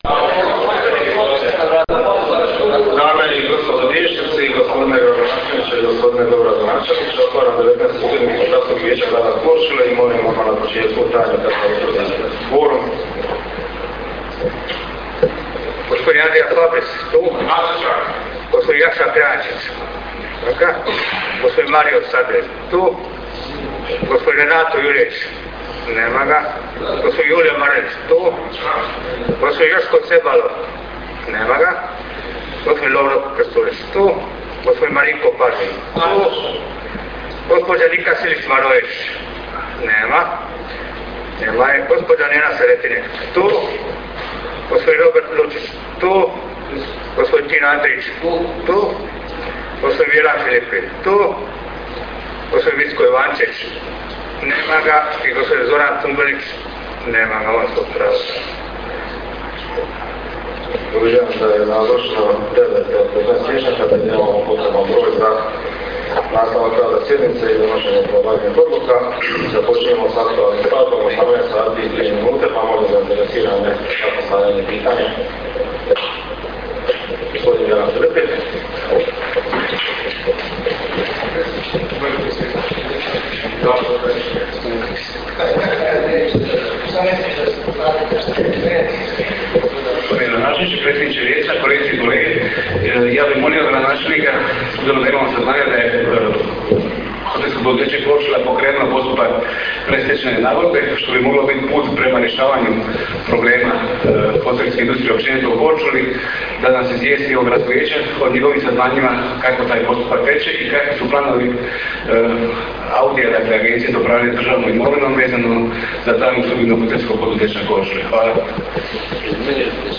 Sjednica je održana 25. siječnja (petak) 2013. godine, s početkom u 18.00 sati, u Gradskoj vijećnici u Korčuli.
Sjednici je predsjedao Lovro Krstulović – predsjednik Vijeća.
Sjednica se prenosila putem Radio Korčule.